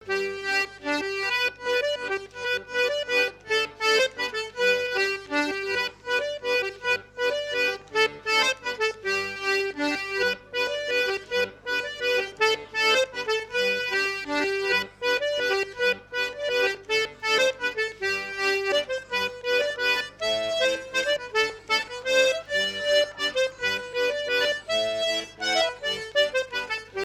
Mazurka
danse : mazurka
Fête de l'accordéon
Pièce musicale inédite